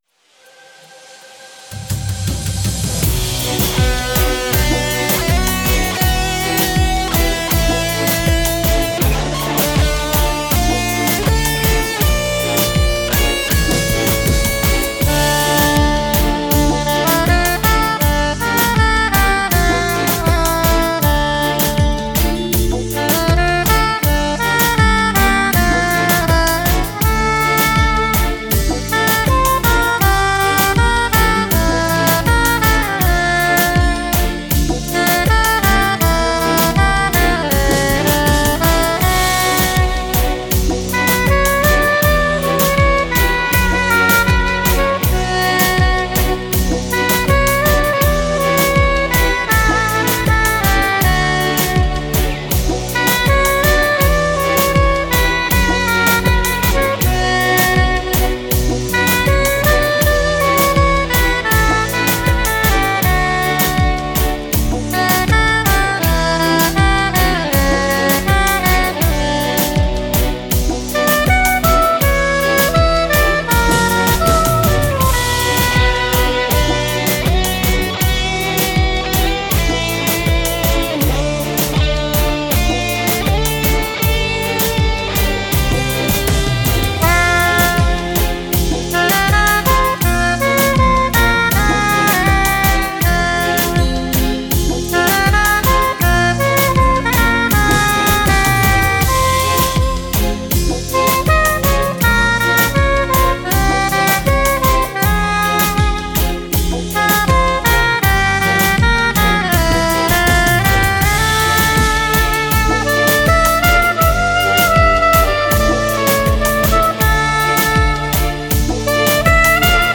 Mix Kizomba – versione per Sax soprano